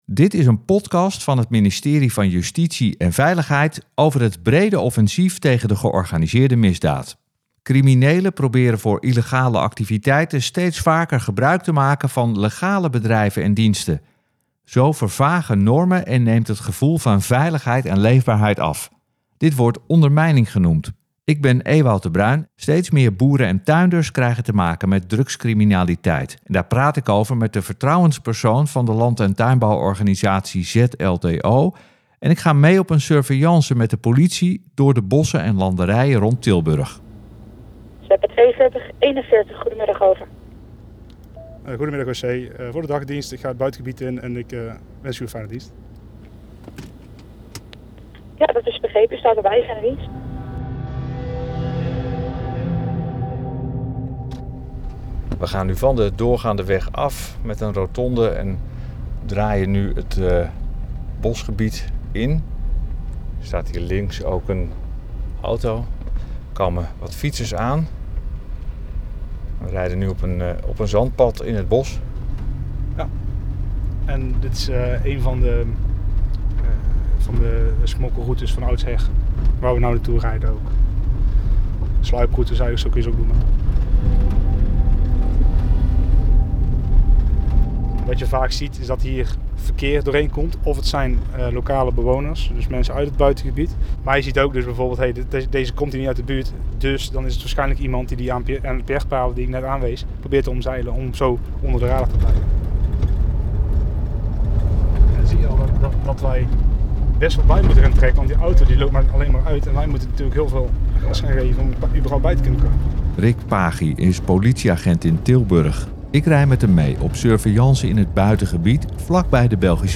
00:00:50 Een spannend geluid is te horen.